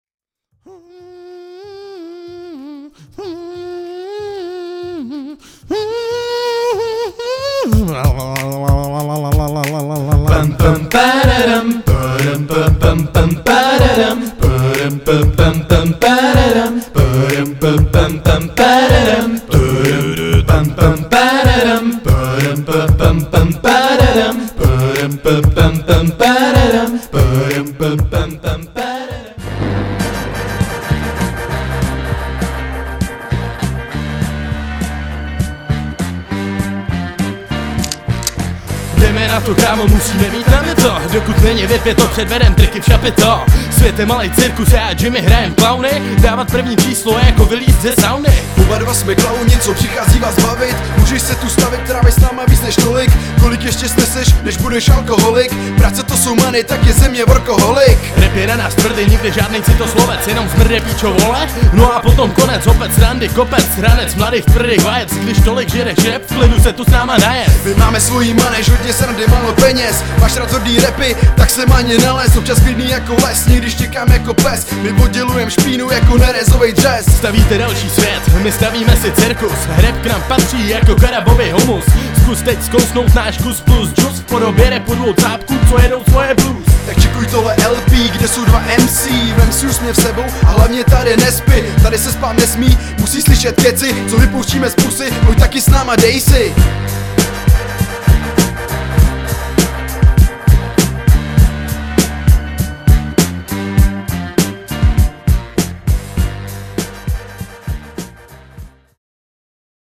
1 Styl: Hip-Hop Rok